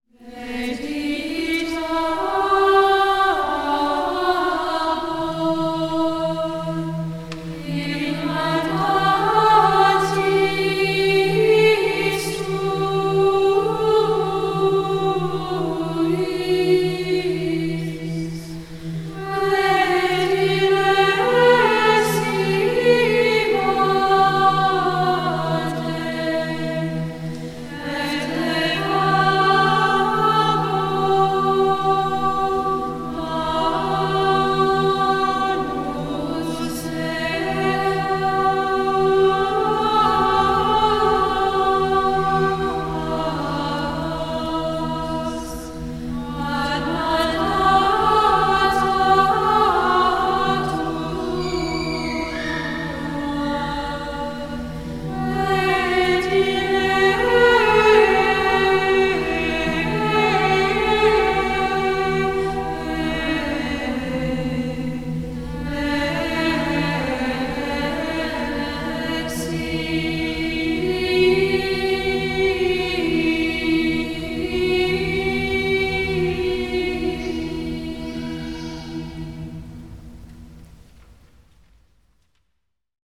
* Mp3 Download • Live Rec.
Moreover, I have been able to recruit some fantastic singers, as you can hear by this live audio recording from one of our Masses: * Mp3 Download • Live Rec.
Now, we have nearly doubled in size, with 20 to 22 singers—primarily high school and college-aged voices; all with minimal recruiting on my part.